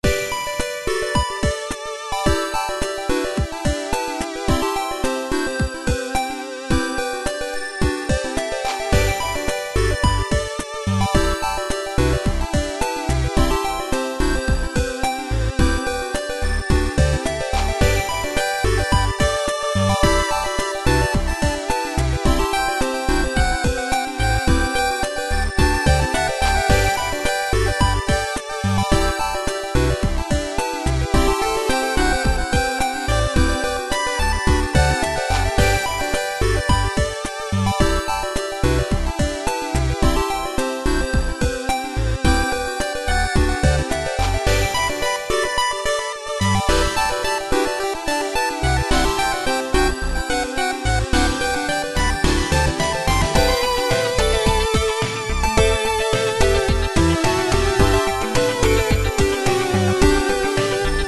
Восьмибитная мелодия (музыку 8 бит скачать для монтажа можно здесь)